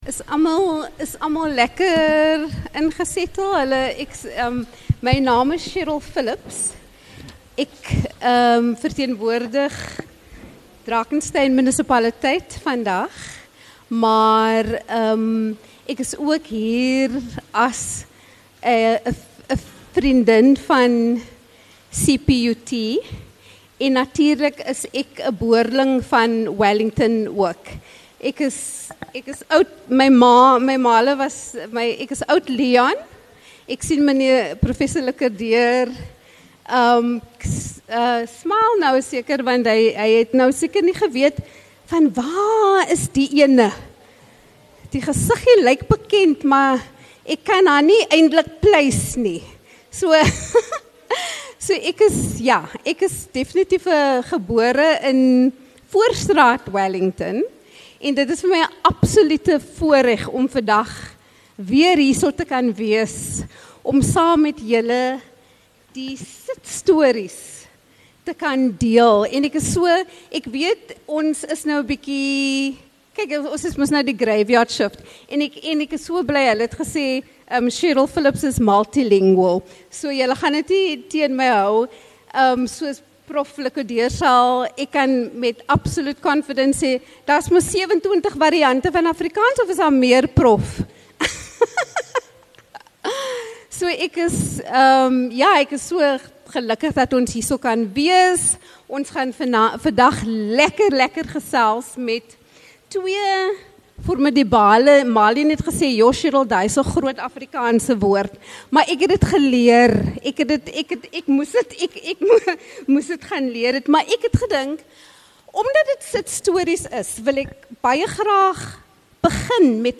Gebaseer op persoonlike vertellings, ondersoek hierdie gesprek